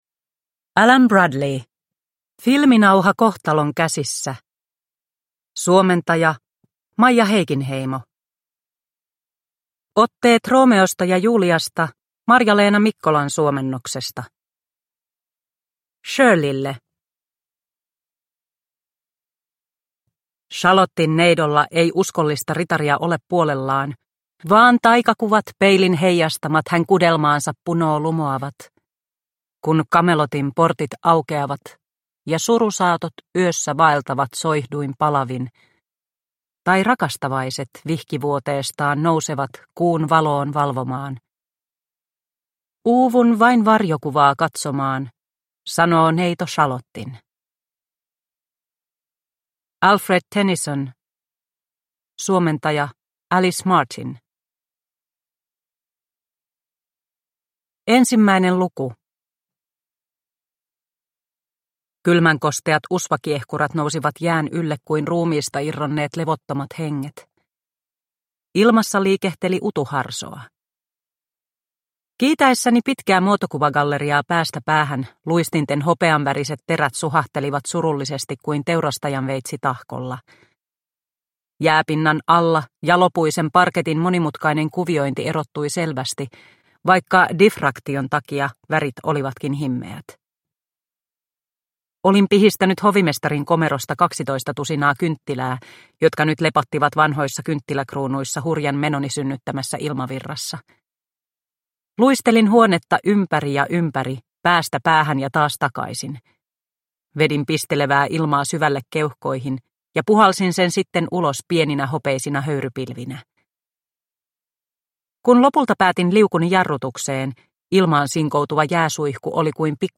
Filminauha kohtalon käsissä – Ljudbok – Laddas ner